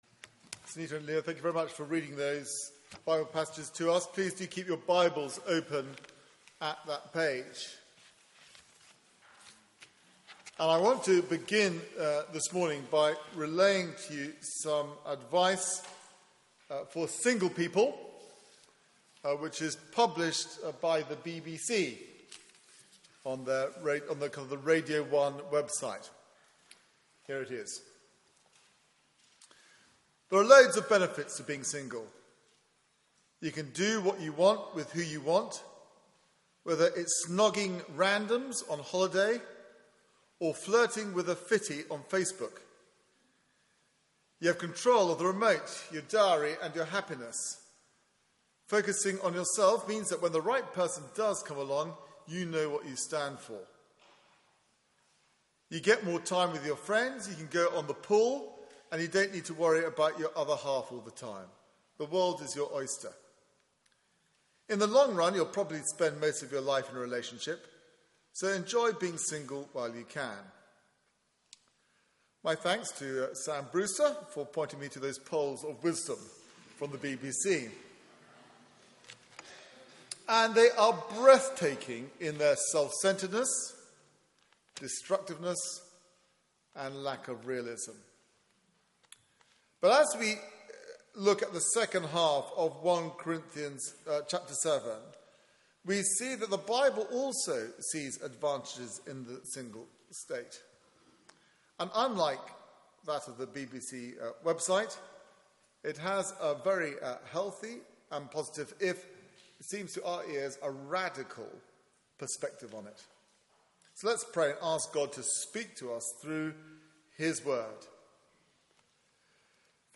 Media for 9:15am Service on Sun 03rd May 2015
Theme: Undivided devotion Sermon